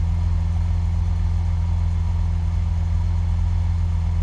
rr3-assets/files/.depot/audio/Vehicles/v12_04/v12_04_idle.wav
v12_04_idle.wav